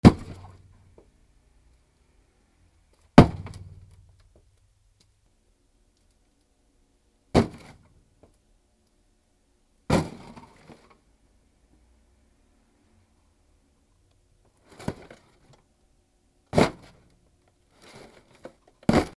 ダンボール殴る
/ H｜バトル・武器・破壊 / H-35 ｜打撃・衝撃・破壊　強_ナチュラル寄り
『ボゴ』